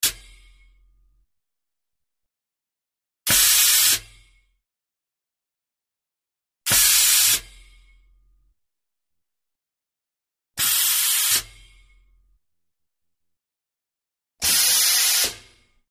Oxygen Tank; Releases 1; Five Oxygen Tank Releases With Tank Reverberations; Varying Lengths, Close Perspective. Pressurized Gas.